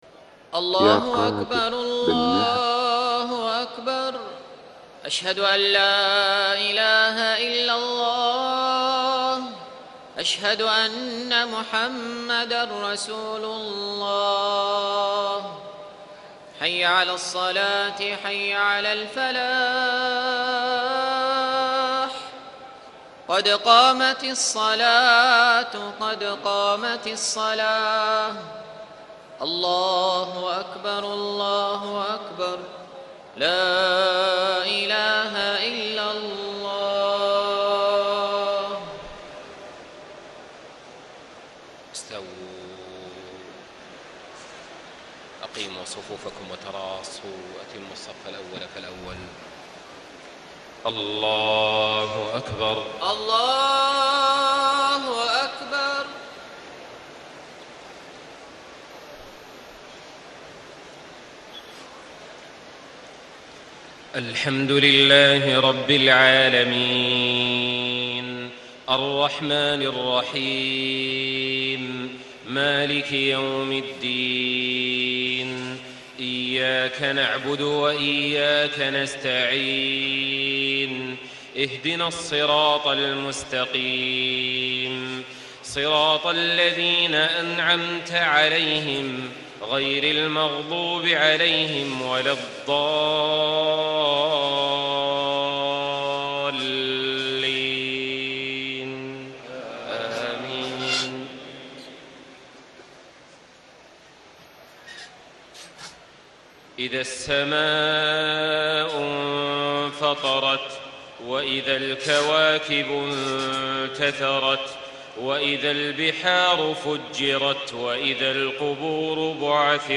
عشاء 18 رمضان 1435 سورة الانفطار > 1435 🕋 > الفروض - تلاوات الحرمين